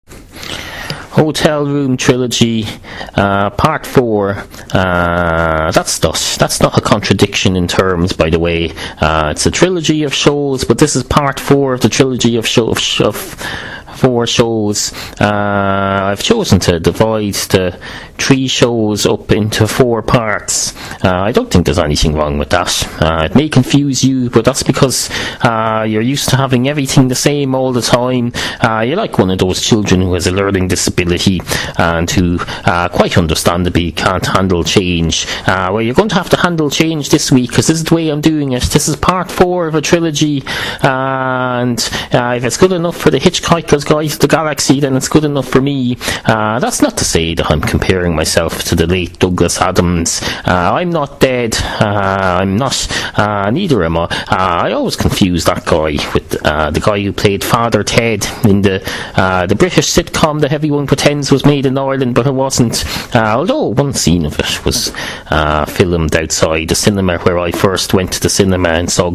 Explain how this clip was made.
Part four in a trilogy of five, recorded in the sanctuary of a hotel bedroom and bathroom, somewhere in central Ireland.